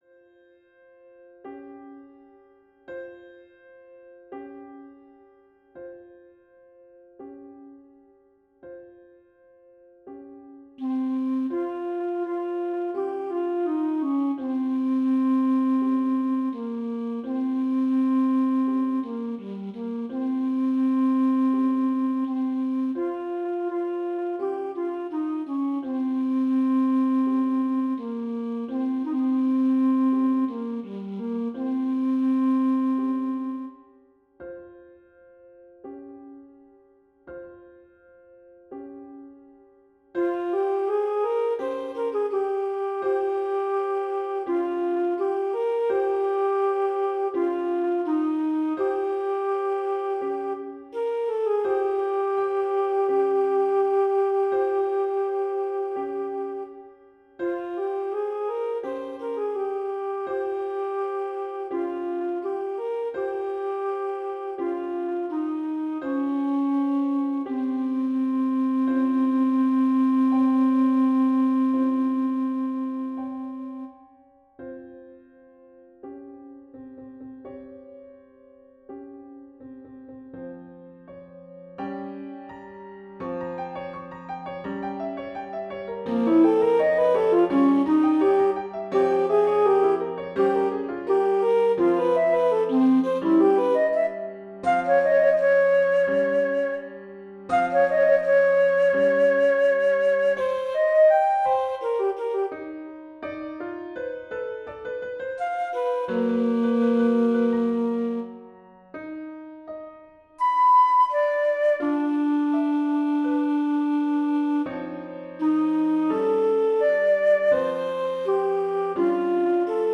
For Alto Flute with Piano Accompaniment